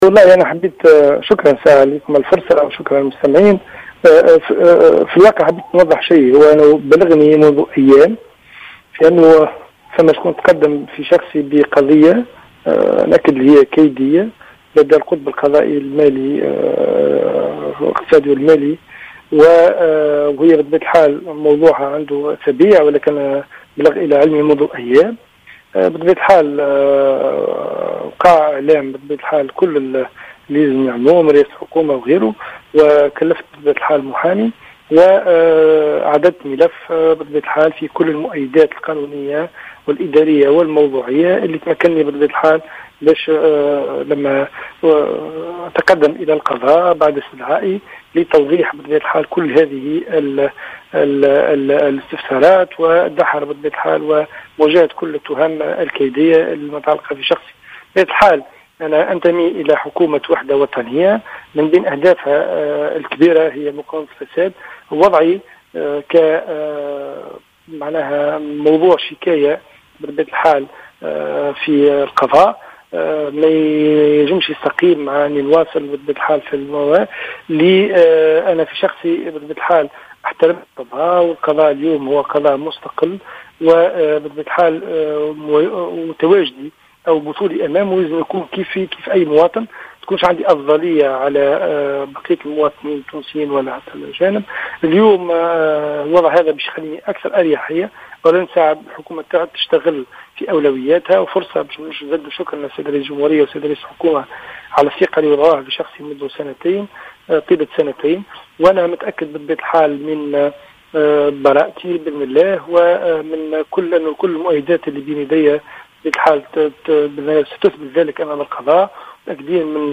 أكد كاتب الدولة المكلف بالمناجم المقال هاشم الحميدي في تصريح للجوهرة "اف ام" منذ قليل أن التهم الموجهة له والتي أدت لإعلان اقالته اليوم من منصبه هي تهم كيدية وليس لها أي أساس من الصحة.